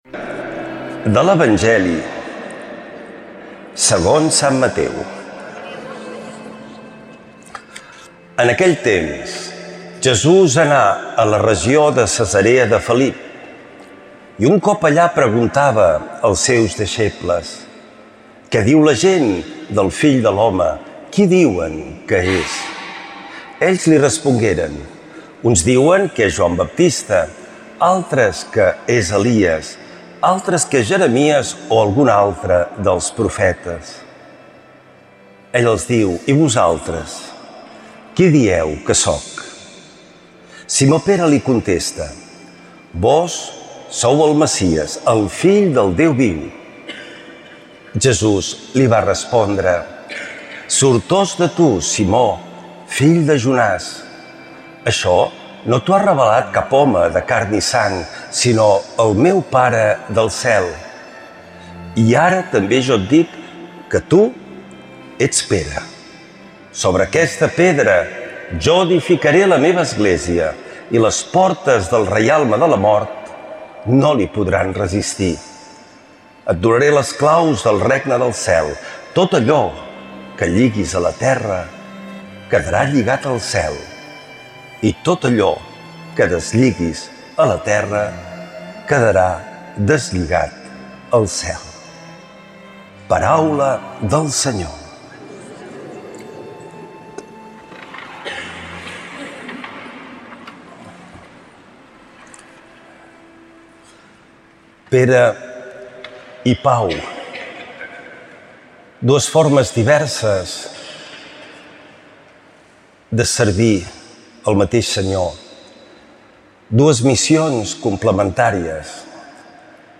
%20Mt%2016,13-19.mp3 Lectura de l’Evangeli segons Sant Mateu.